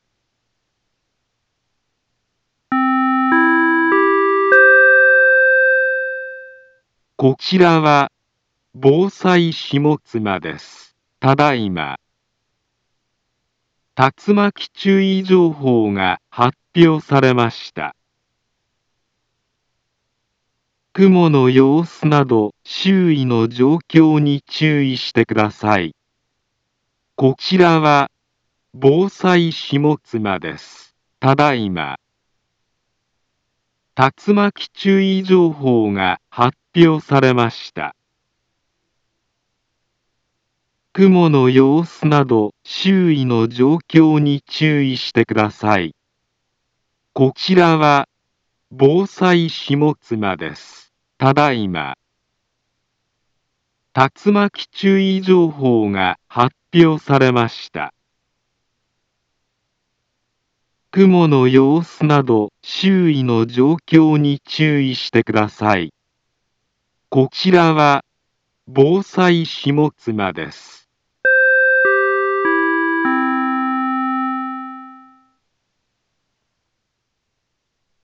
Back Home Ｊアラート情報 音声放送 再生 災害情報 カテゴリ：J-ALERT 登録日時：2025-08-08 15:58:34 インフォメーション：茨城県北部、南部は、竜巻などの激しい突風が発生しやすい気象状況になっています。